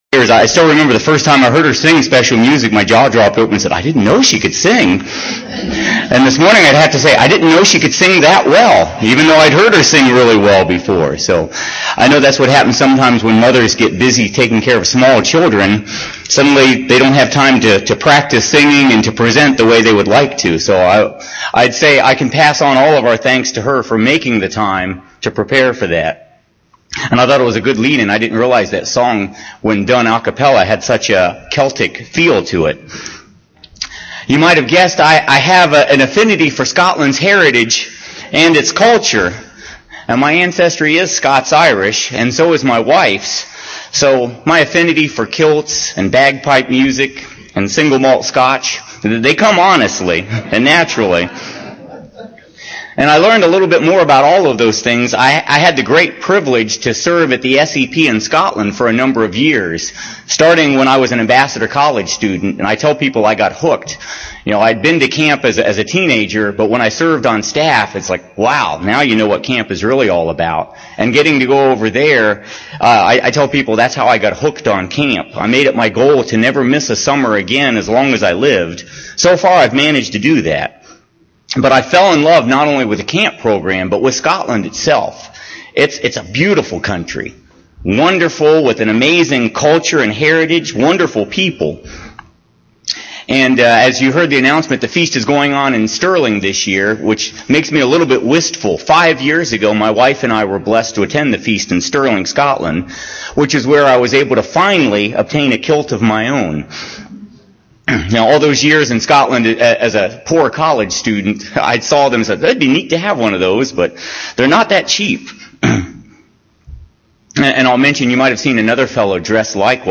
This sermon was given at the Cincinnati, Ohio 2013 Feast site.